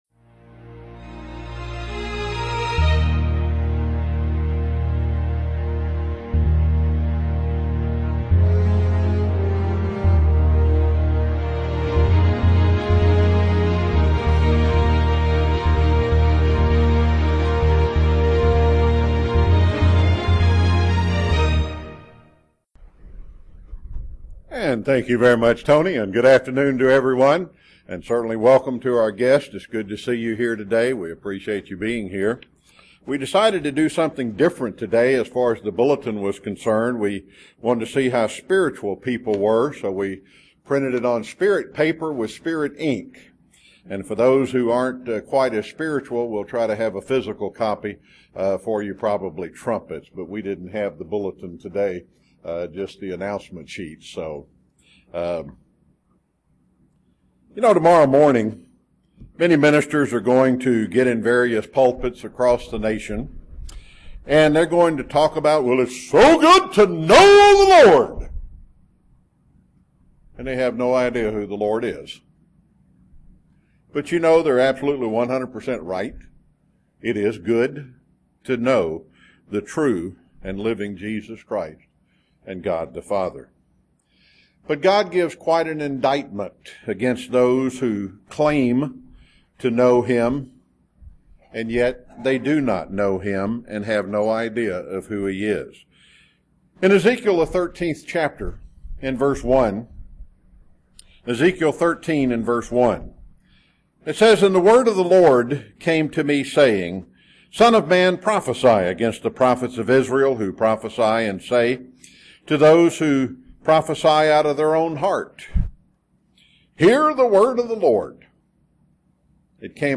Print Having a relationship with God UCG Sermon Studying the bible?